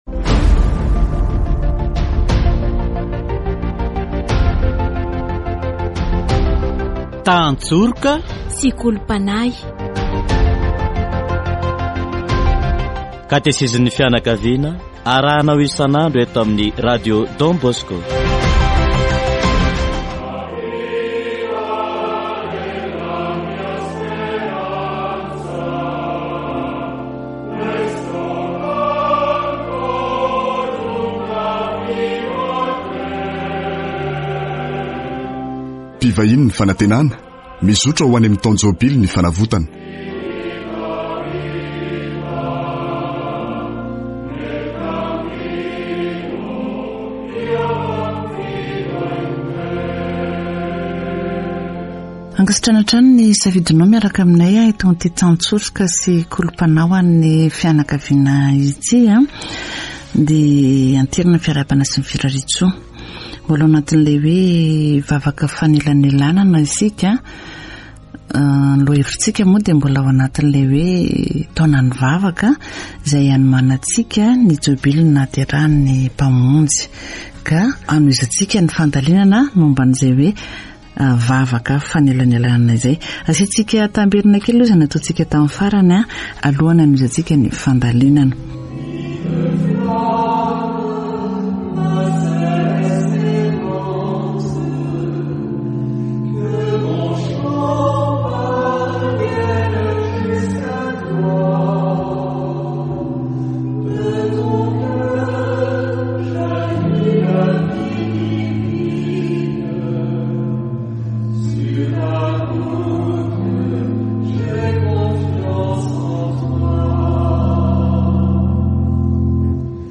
Catechesis on intercessory prayer